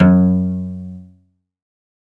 SOLO CEL.4-L.wav